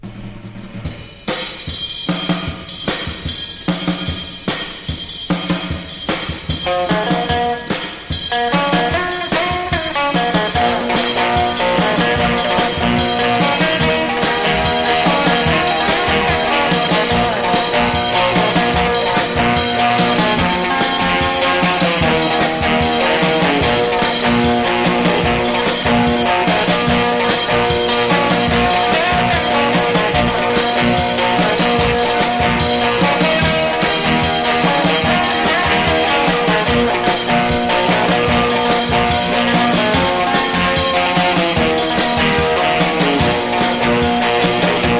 Rock’N’Roll, Garage Rock　USA　12inchレコード　33rpm　Mono